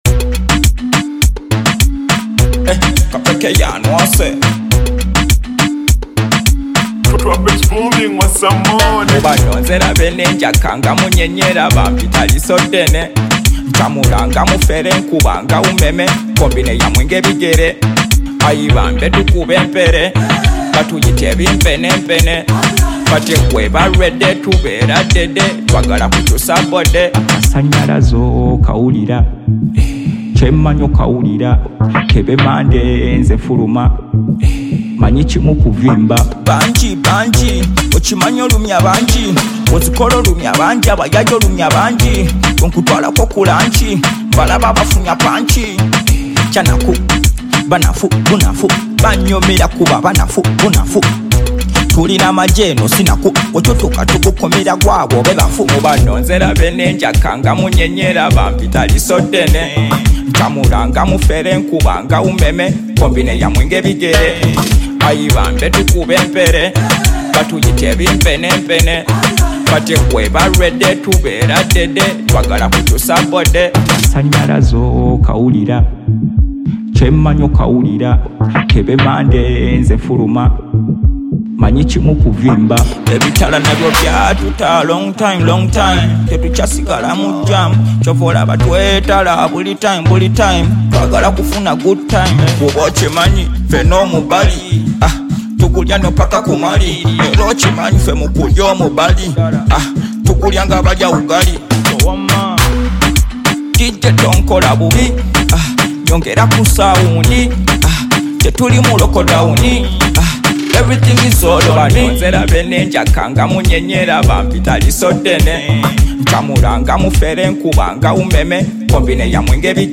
Genre: UG Hip-Hop.